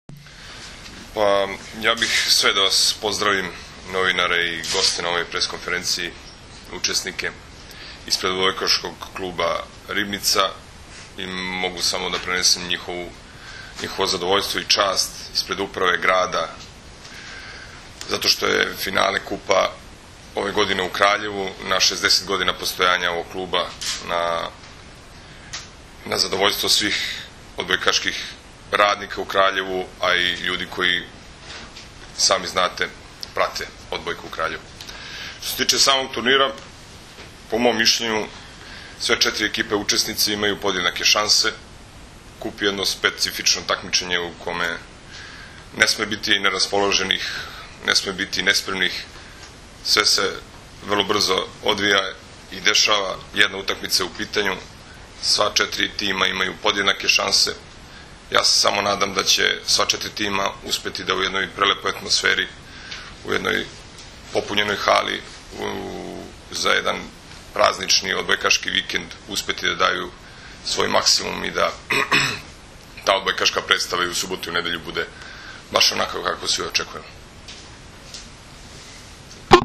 U prostorijama Odbojkaškog saveza Srbije danas je održana konferencija za novinare povodom Finalnog turnira 49. Kupa Srbije u konkurenciji odbojkaša, koji će se u subotu i nedelju odigrati u Hali sportova u Kraljevu.